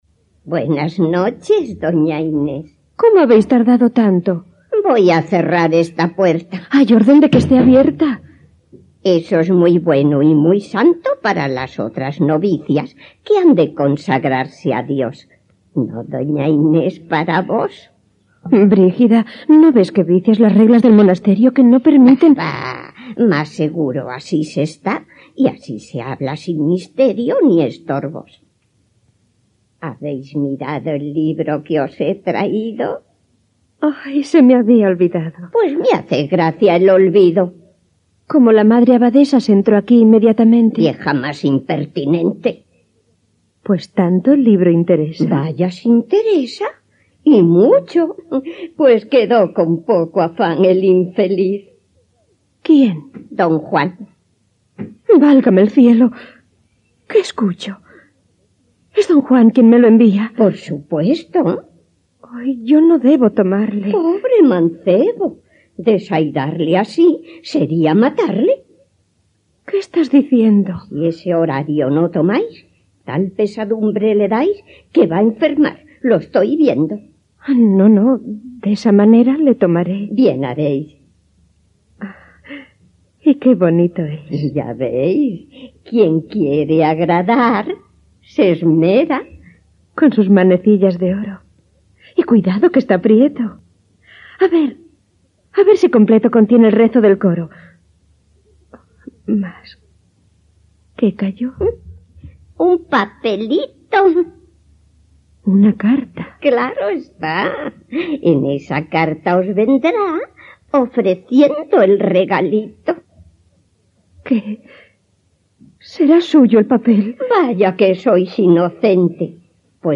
Adaptació radiofònica de Don Juan Tenorio, de José Zorrila. Diàleg entre Brígida i Doña Inés
Ficció